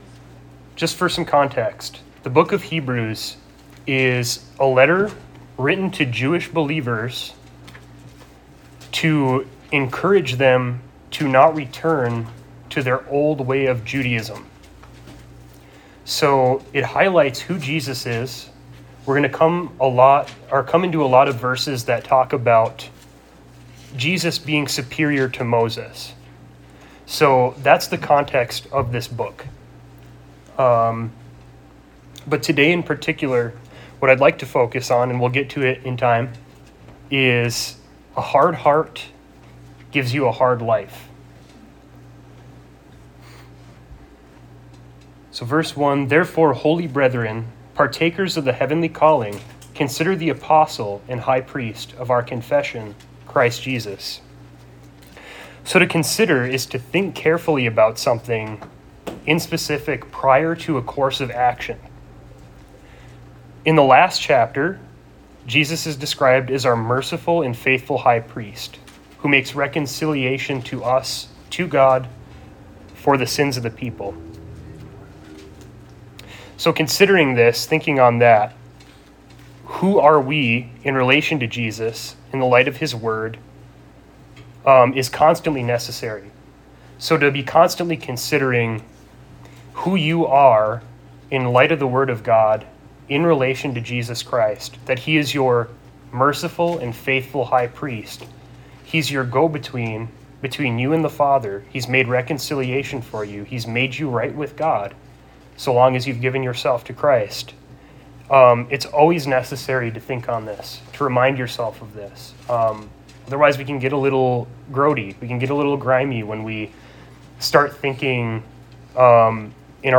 Series: Guest Speaker Service Type: Sunday Morning « “You Are Not a Victim” Genesis39-40 Genesis 41 “Faithful in Little